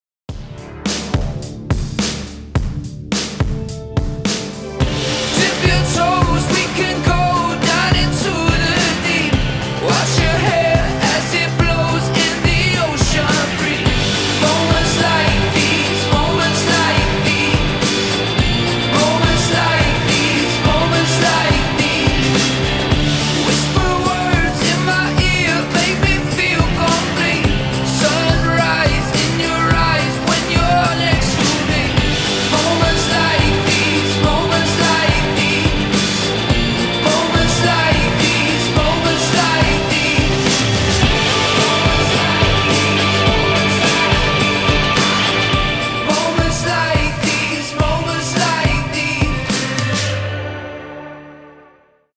• Качество: 320, Stereo
позитивные
мужской вокал
спокойные
релакс
Pop Rock
вдохновляющие
Джингл из рекламы турецкого отеля с Натальей Водяновой